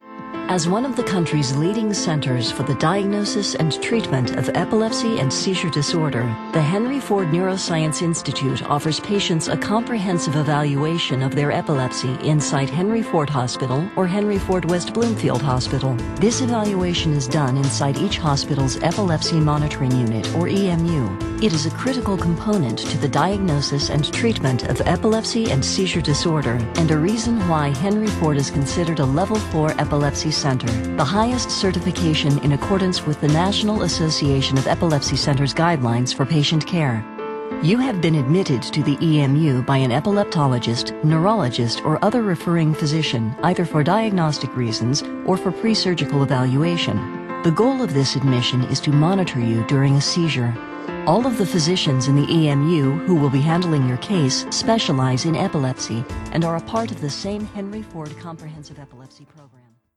Experienced female voice artist with a rich, textured sound, authoritative yet warm
mid-atlantic
Sprechprobe: Industrie (Muttersprache):